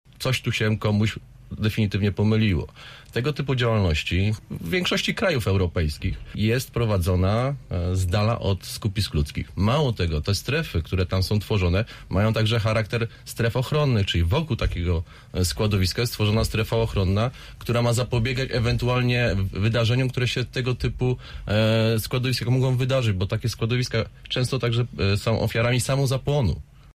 O zagrożeniach, jakie niosą ze sobą składowiska odpadów w Przylepie i centrum Zielonej Góry, mówili dzisiaj na antenie naszego radia goście Politycznego Podsumowania Tygodnia.